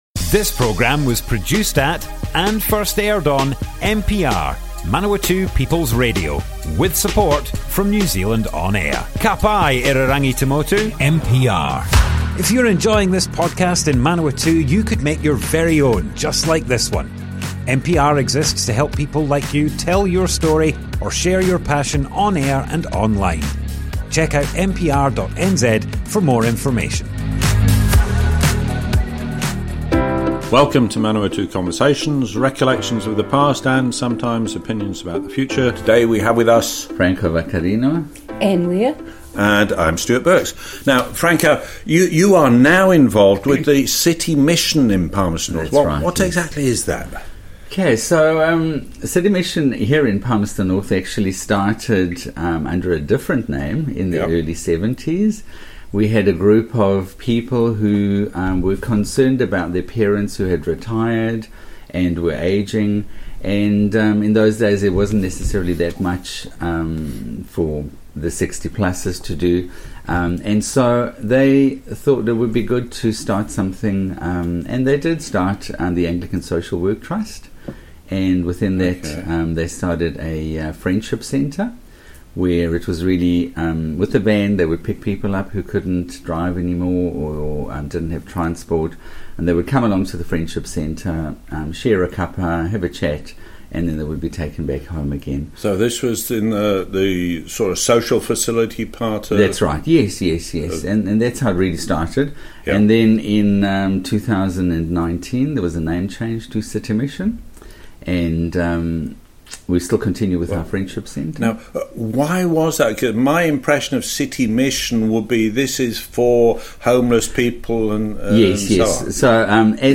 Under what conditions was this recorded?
Manawatu Conversations More Info → Description Broadcast on Manawatu People's Radio, 18th November 2025, Part 1 of 2. The City Mission began in the 1970s as the Anglican Social Work Trust in Palmerston North.